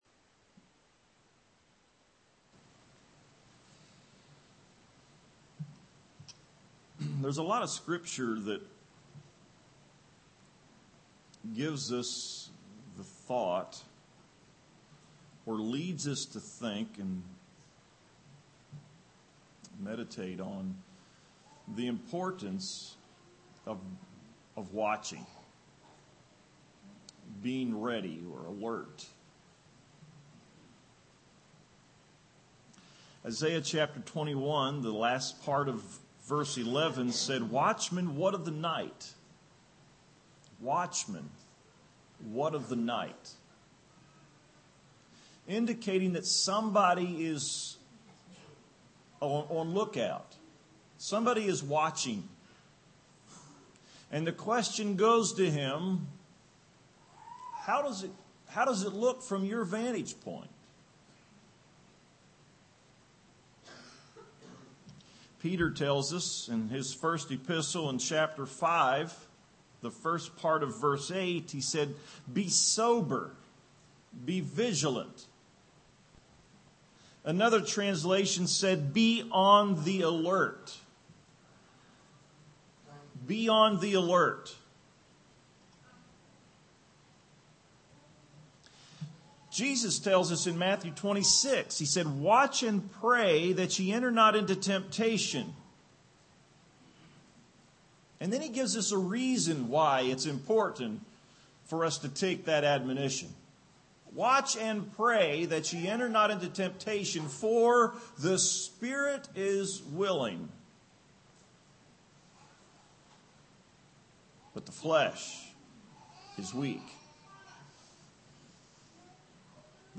Tagged with prayer , vigilance , watchfulness , watchman